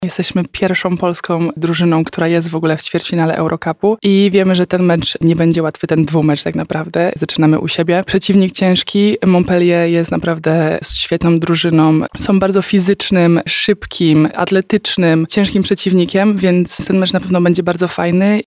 -mówiła w Porannej Rozmowie Radia Centrum zawodniczka zespołu z Lublina